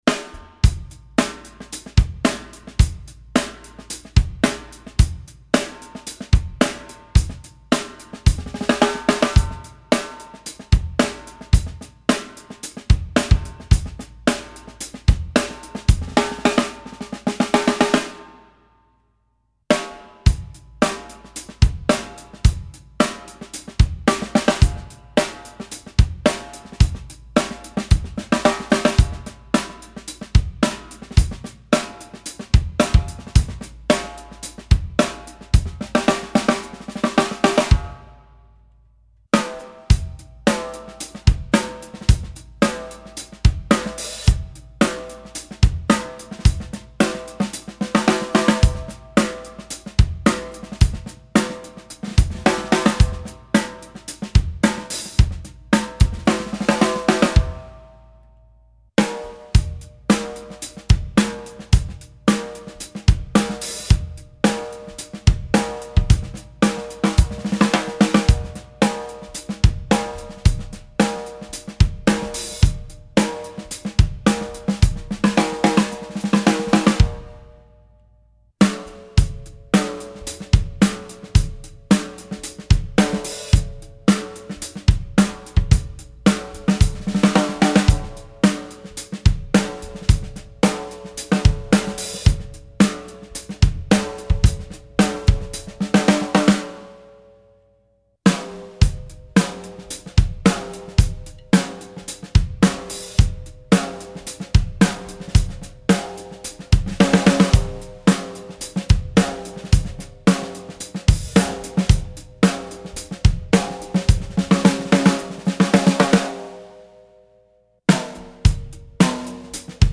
The only non-Ego parts are the Evans drumheads (coated G1 batter, Hazy 200 resonant) and PureSound snare wires.
I ran the drum through low, medium, and high tunings, starting with the batter at medium tension.
The process of steam bending puts a certain amount of tension into the wood, resulting in a naturally higher pitch, which was offset by the earthy tonality of the myrtle.
In the high and medium tuning range, without muffling, the Ego shell’s overtones were housed in the midrange frequencies from 400 to 900 Hz, with more pronounced overtones appearing toward the edges of the spectrum. Minimal muffling helped shave off the edginess for a more focused sound. The snare response was clear and articulate but wouldn’t be considered bright or snappy. Low tuning maintained a fat attack, but the tone started to warble a bit if I didn’t use any muffling.
Ego-snare.mp3